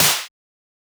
trunk snare.wav